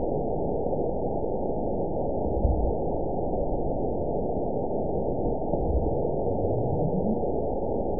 event 920412 date 03/23/24 time 21:56:43 GMT (1 year, 1 month ago) score 9.56 location TSS-AB04 detected by nrw target species NRW annotations +NRW Spectrogram: Frequency (kHz) vs. Time (s) audio not available .wav